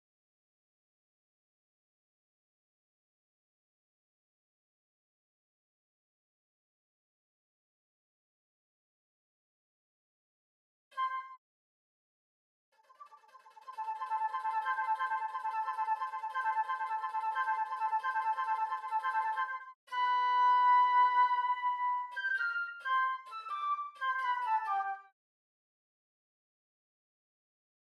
2. Flute (Flute/Normal)
Holst-Jupiter-mod-30-Flute_0.mp3